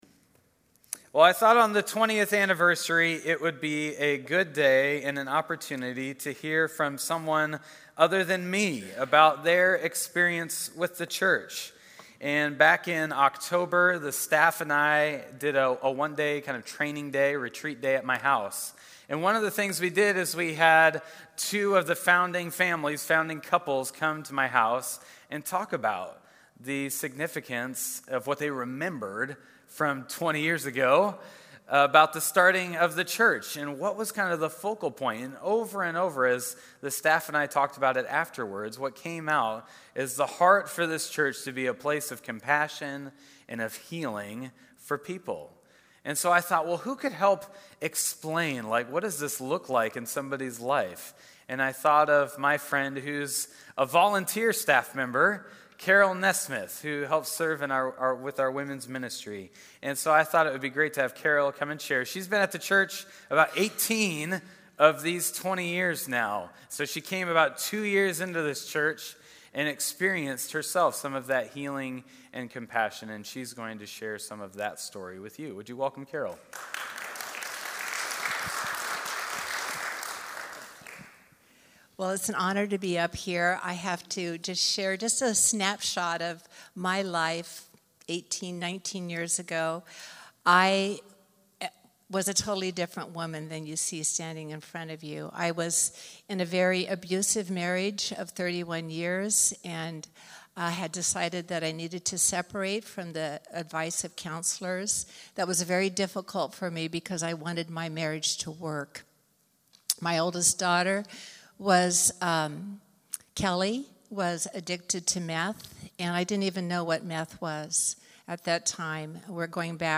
Sermon audio from January 28